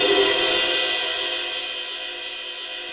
Index of /90_sSampleCDs/Roland L-CDX-01/CYM_FX Cymbals 1/CYM_Cymbal FX
CYM SIZZLE07.wav